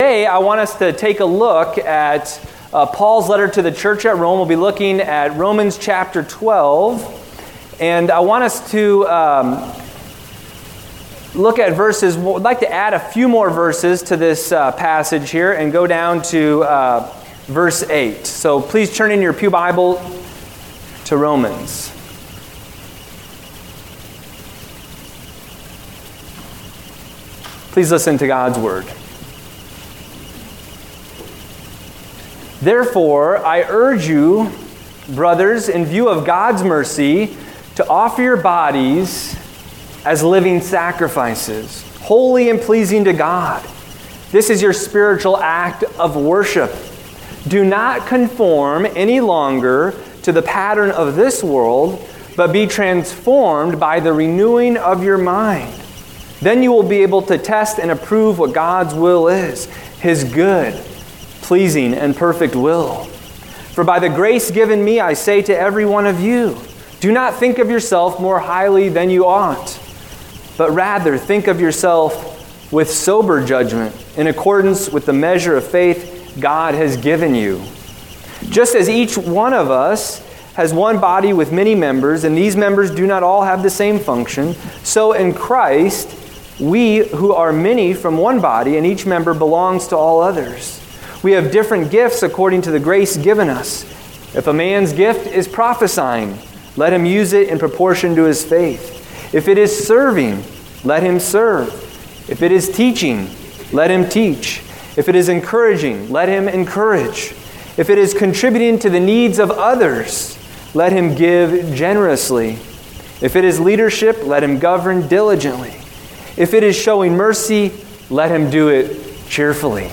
Recent Sermons
Service Type: Sunday Morning